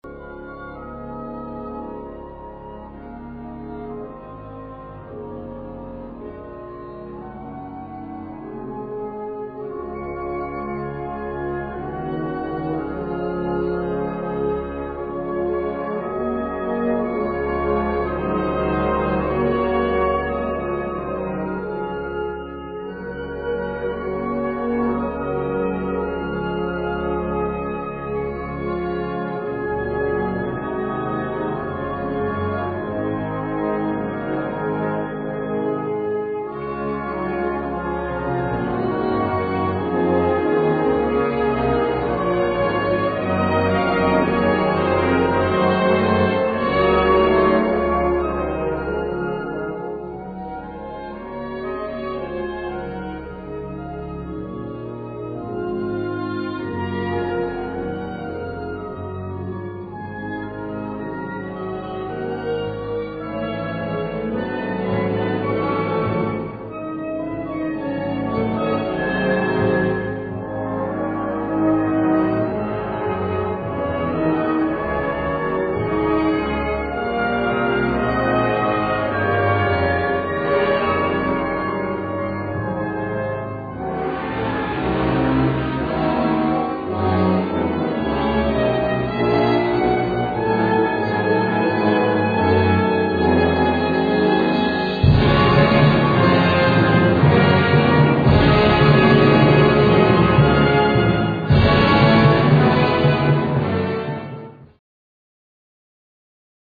Kategorie Blasorchester/HaFaBra
Besetzung Ha (Blasorchester)